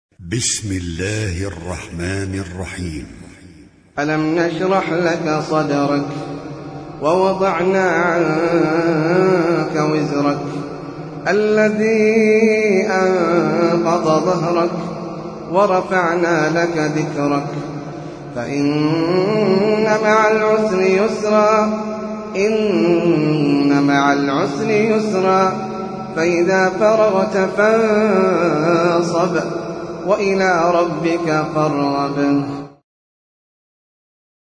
سورة الشرح - المصحف المرتل (برواية حفص عن عاصم)
جودة عالية